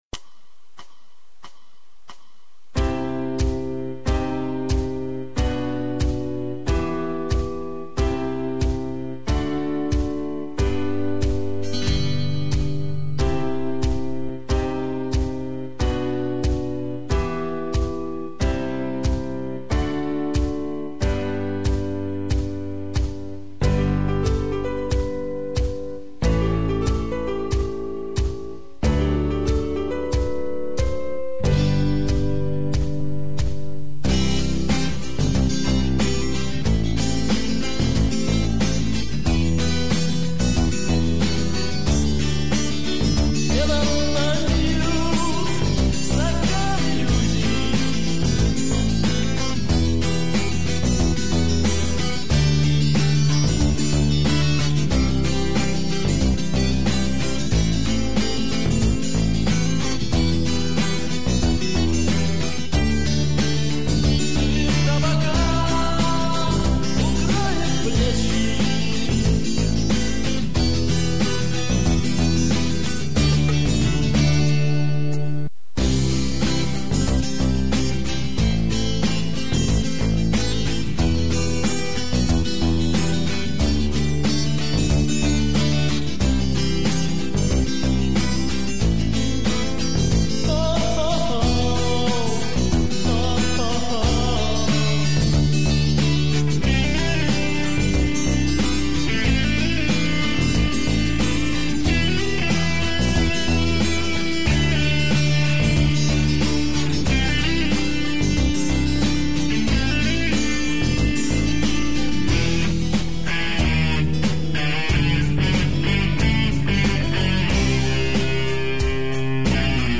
минусовка версия 229589